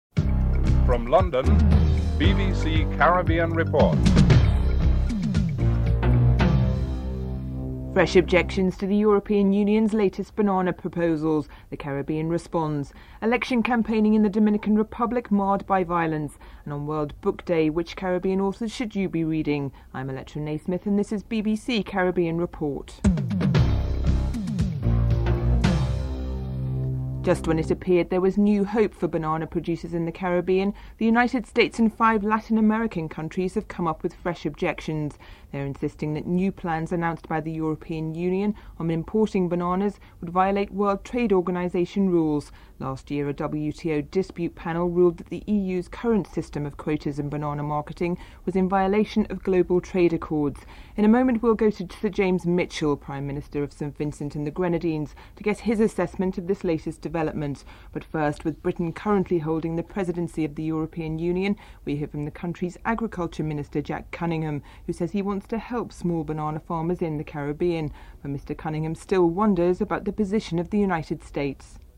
Interviews with Earl Lovelace and Pauline Melville (12:13-15:15)